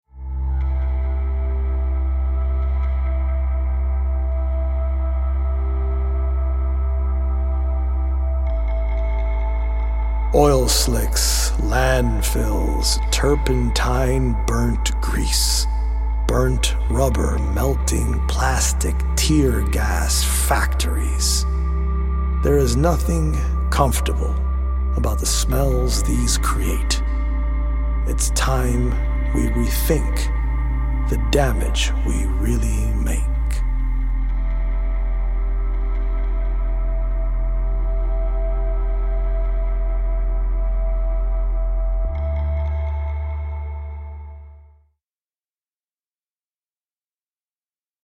audio-visual poetic journey
healing Solfeggio frequency music
EDM producer